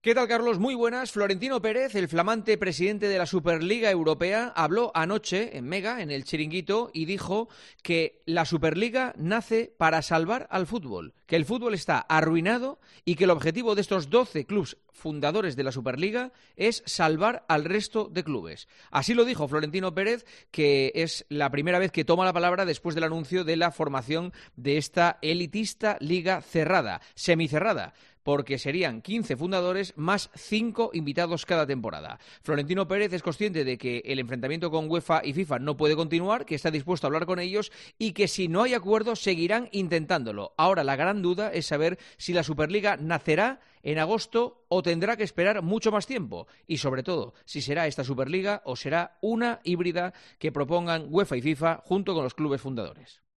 El comentario de Juanma Castaño
El director de 'El Partidazo de COPE' analiza la actualidad deportiva en 'Herrera en COPE'